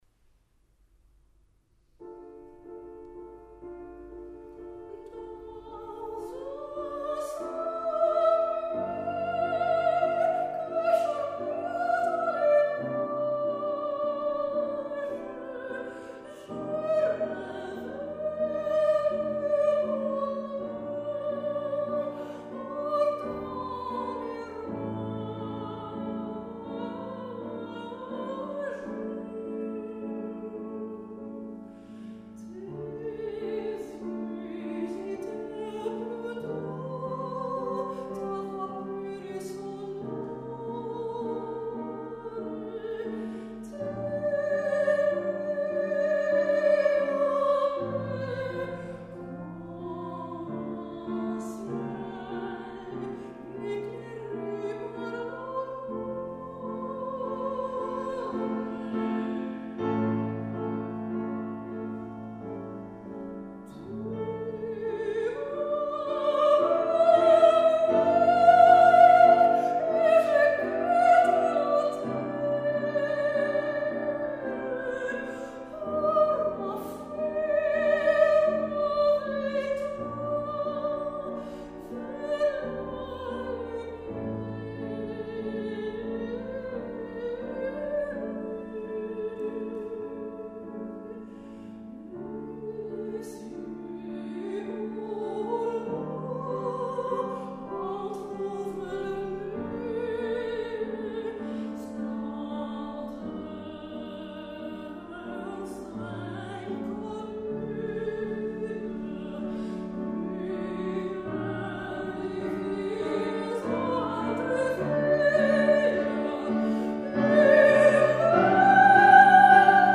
sopraan
pianist